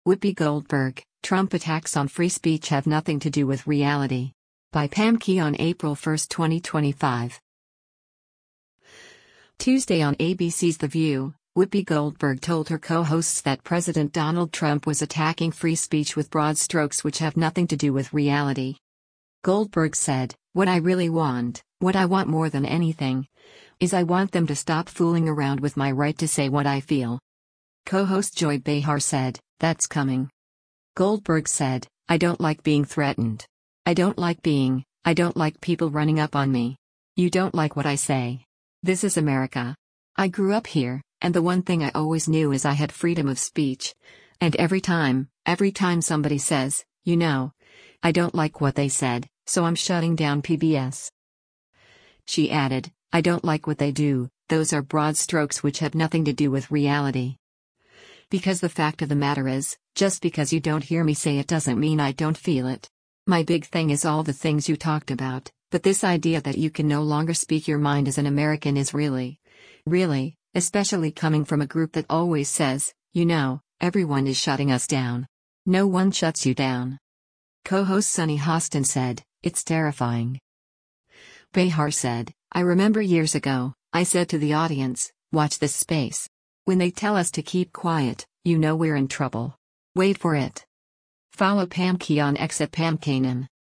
Tuesday on ABC’s “The View,” Whoopi Goldberg told her co-hosts that President Donald Trump was attacking free speech with “broad strokes which have nothing to do with reality.”